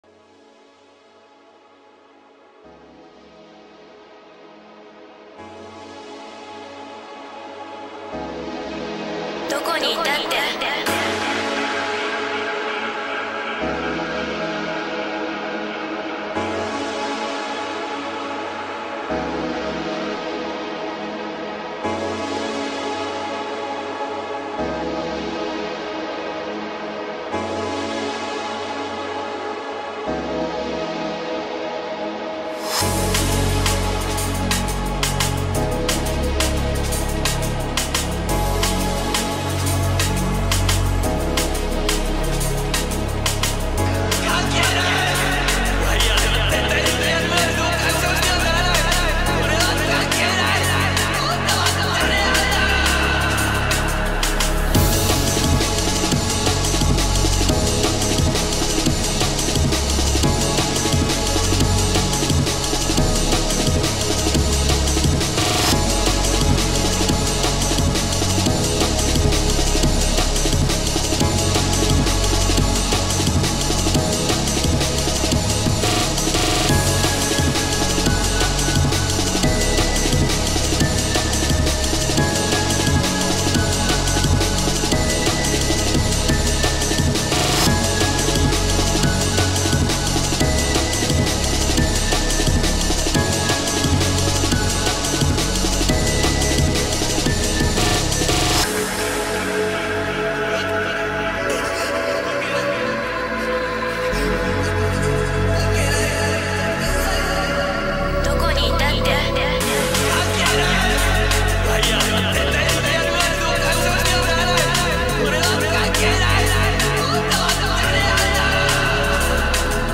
breakcore.mp3